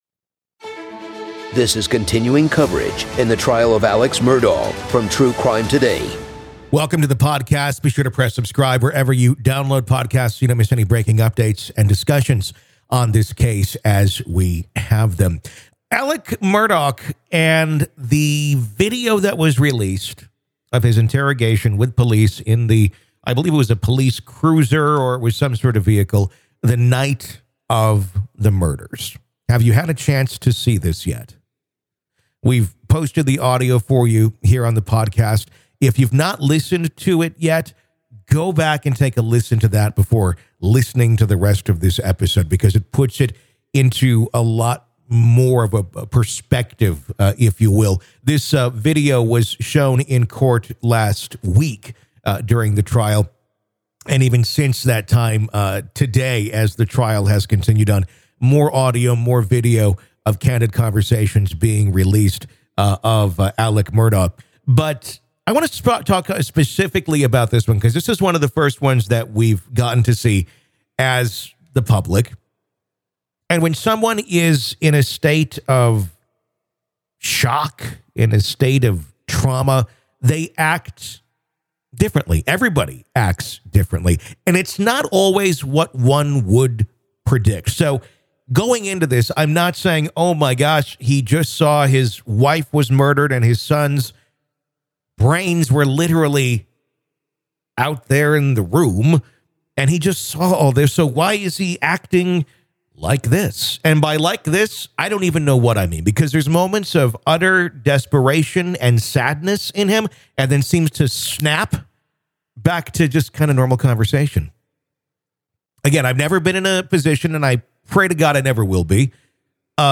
True Crime Today | Daily True Crime News & Interviews / What Does The Alex Murdaugh Interrogation Video Tell Us?